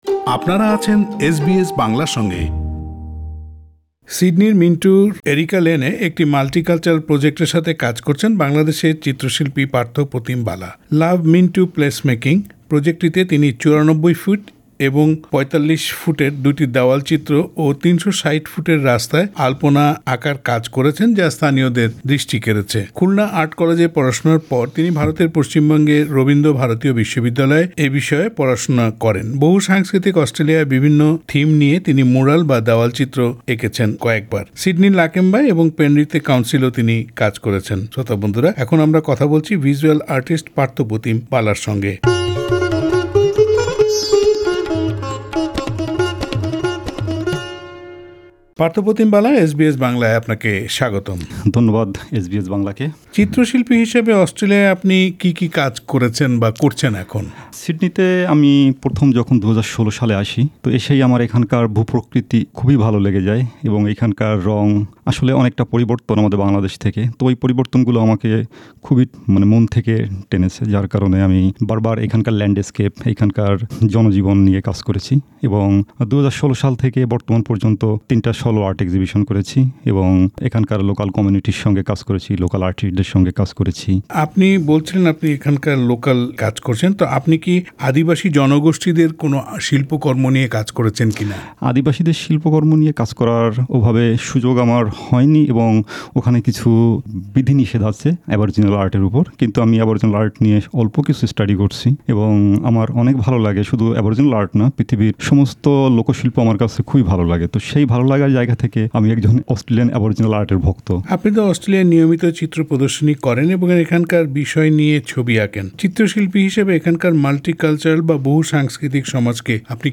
সাক্ষাৎকারটি বাংলায় শুনতে উপরের অডিও প্লেয়ারটিতে ক্লিক করুন।